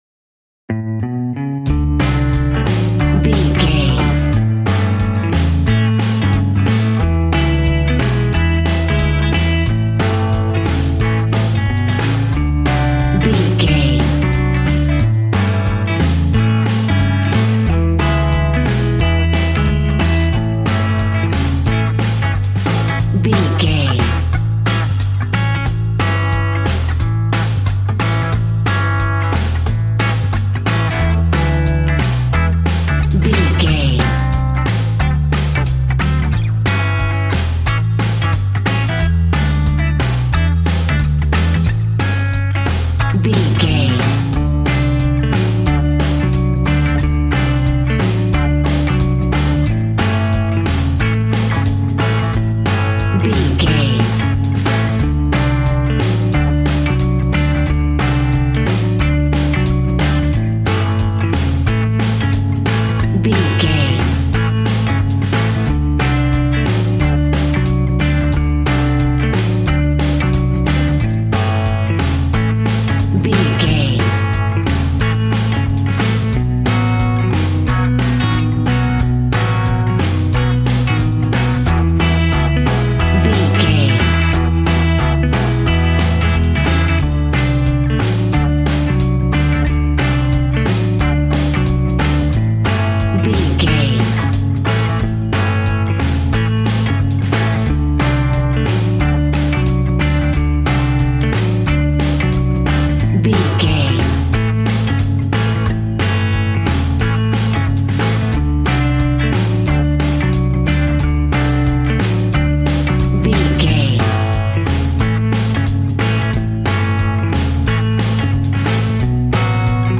Ionian/Major
cool
uplifting
bass guitar
electric guitar
drums
60s
cheerful/happy